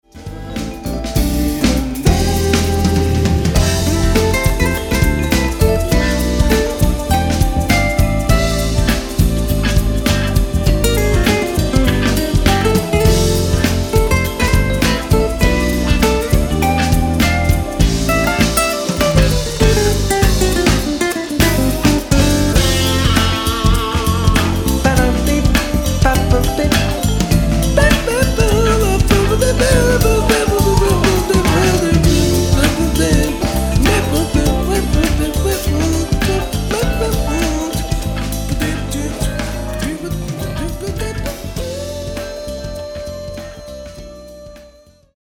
lead vocal
guitars, percussion, backing vocal
keyboards